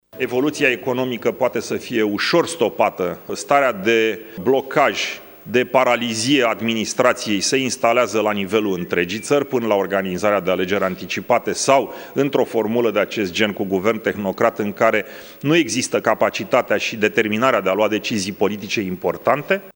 La rândul său, celălalt copreședinte al ALDE, Călin Popescu Tăriceanu, a declarat că formațiunea liberal-democrată nu sprijină varianta alegerilor anticipate: